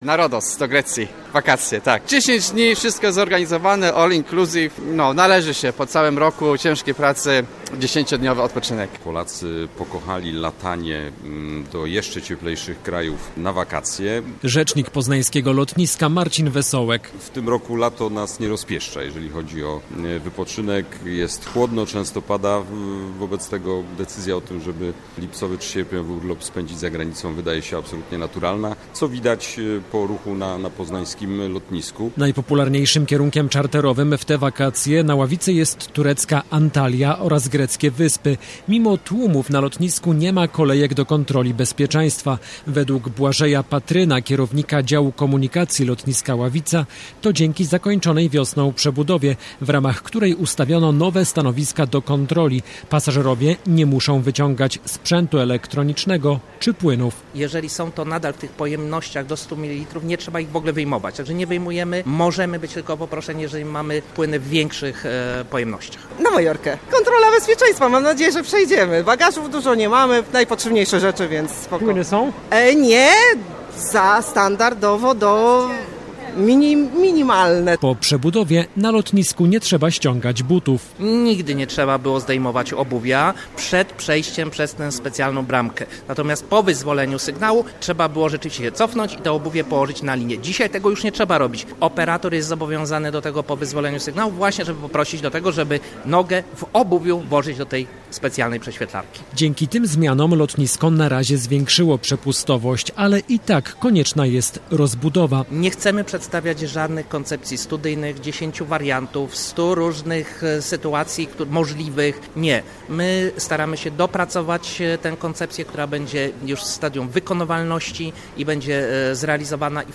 W piątek Radio Poznań od rana nadaje program na żywo z lotniska Ławica.
Nasze studio stanęło w hali odlotów.